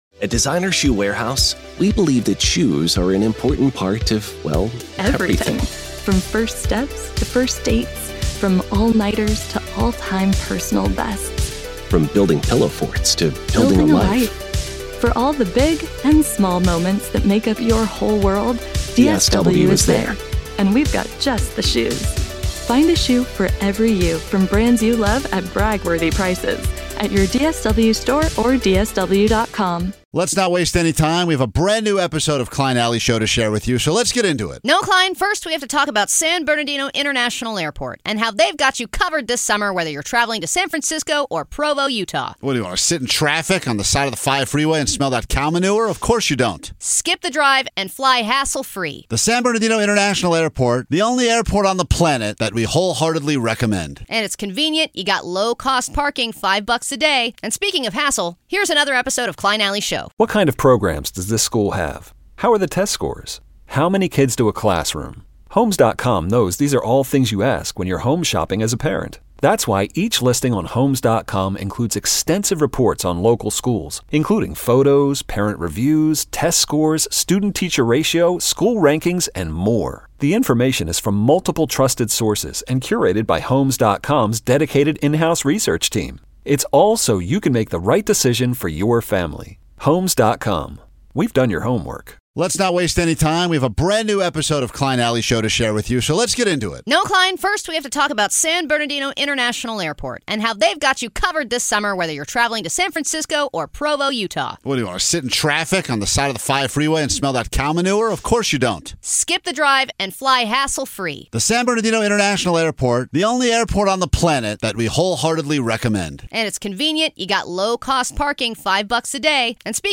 the show is known for its raw, offbeat style, offering a mix of sarcastic banter, candid interviews, and an unfiltered take on everything from culture to the chaos of everyday life.